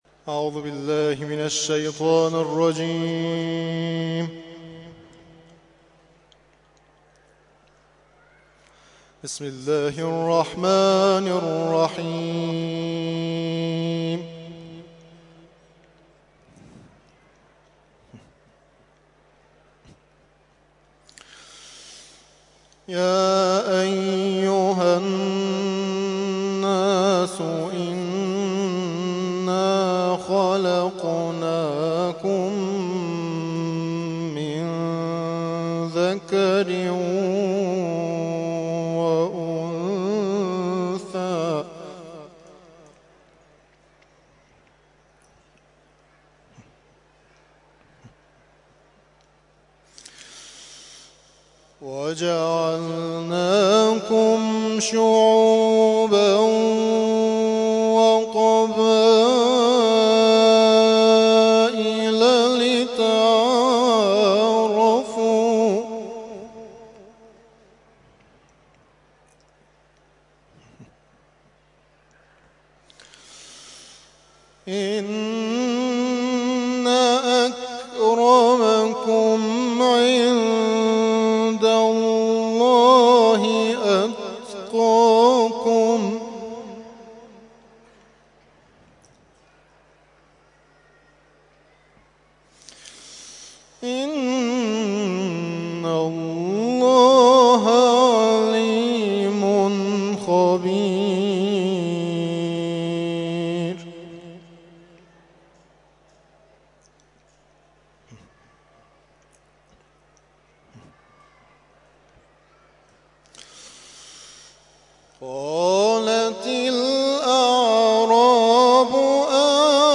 تلاوت مغرب - سوره حجرات آیات(۱۳ تا ۱۸) Download